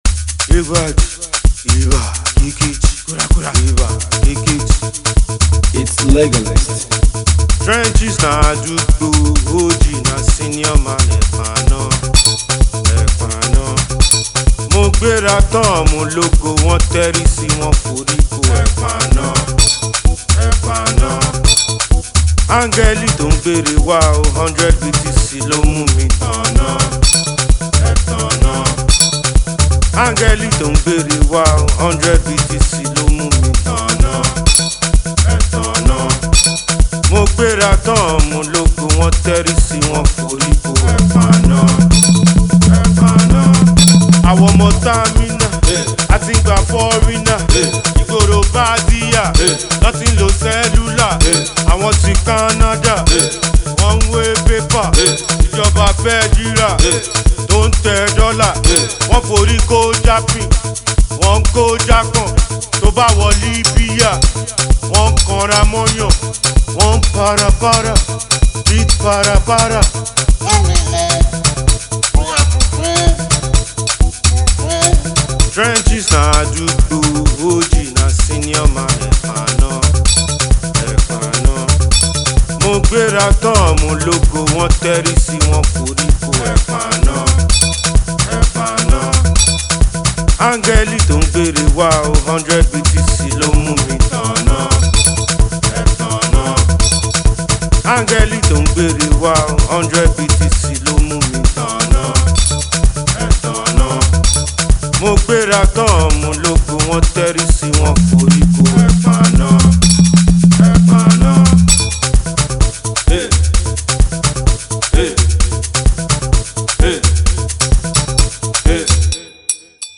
high-energy title track